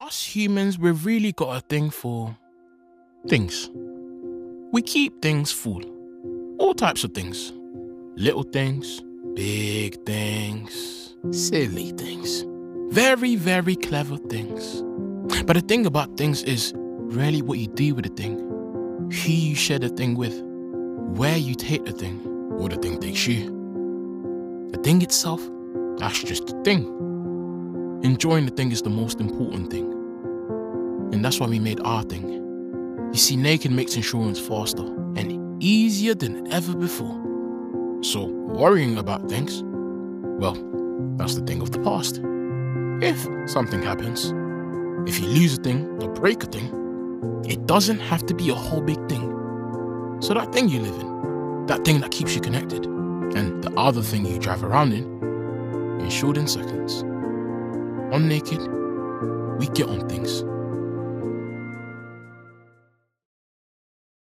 Voice Reel
Commercial - Insurance - Playful, Laidback, Grounded